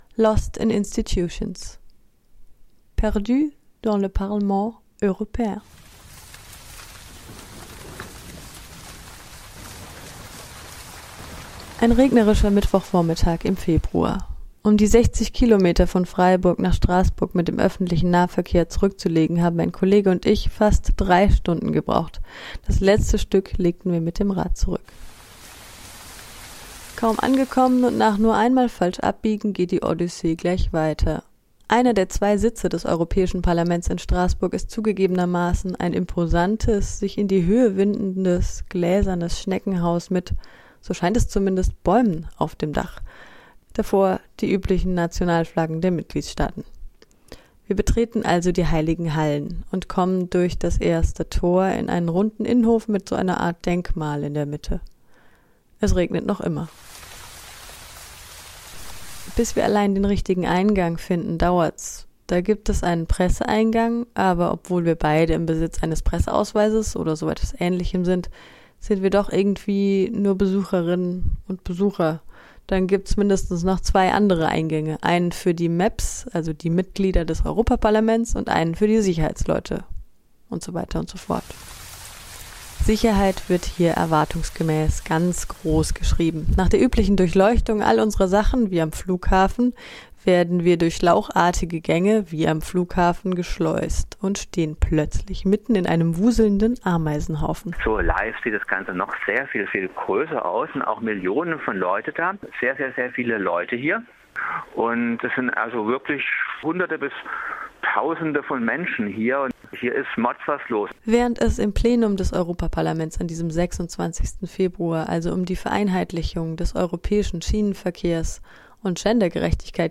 wandern durch die Hallen des Europäischen Parlaments in Straßburg